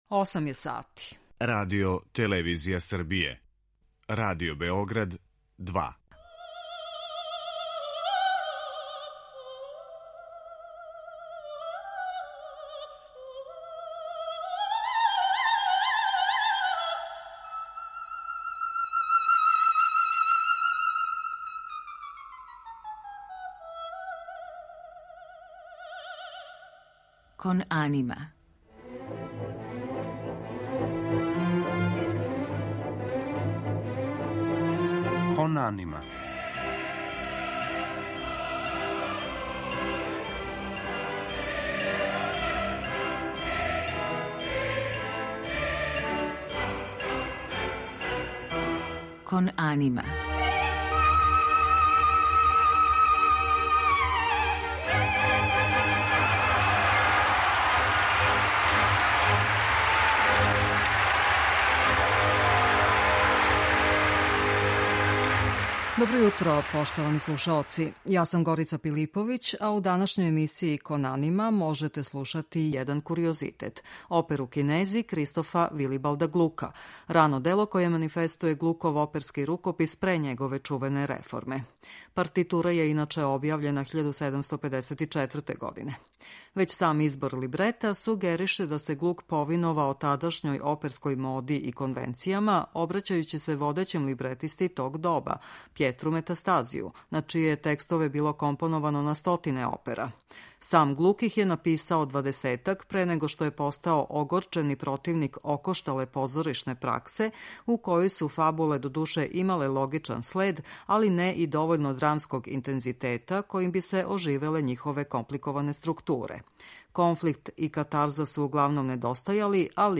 У данашњој емисији Кон анима можете слушати једно рано дело Кристофа Вилибалда Глука ‒ оперу „Кинези”.